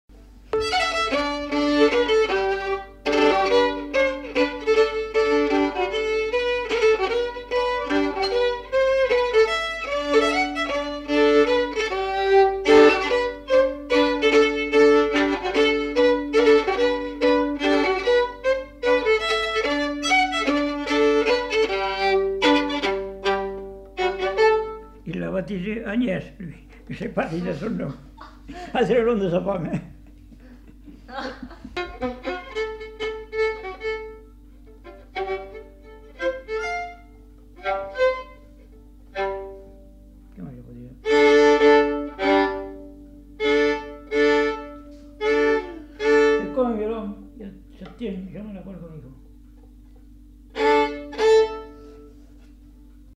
Aire culturelle : Lomagne
Lieu : Garganvillar
Genre : morceau instrumental
Instrument de musique : violon
Danse : mazurka